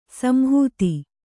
♪ samhūti